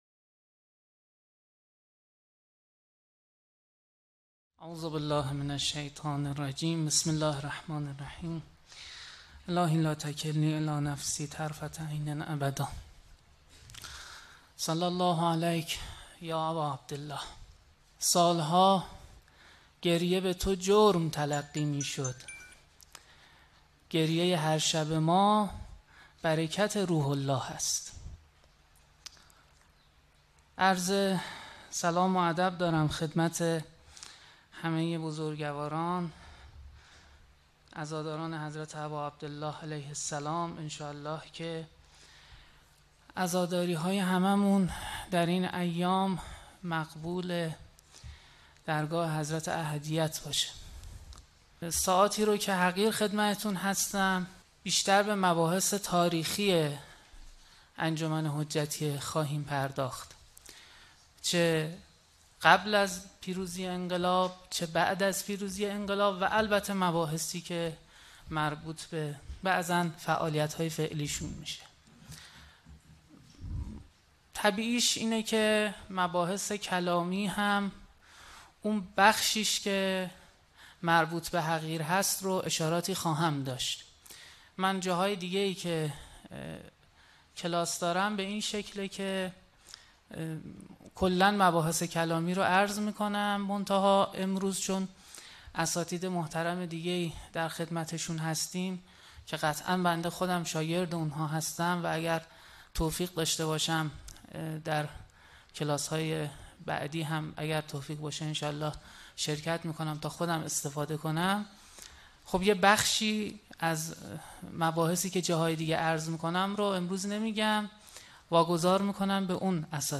این نشست که به همّت مؤسسه حق‌پژوهی و با همکاری دفتر تبلیغات اسلامی خراسان رضوی برگزار شد، شامل چهار جلسه است.
شرکت‌کنندگان به تحلیل و بررسی نقش این انجمن در تحولات مذهبی و سیاسی پرداخته و دیدگاه‌های مختلف را مطرح کردند.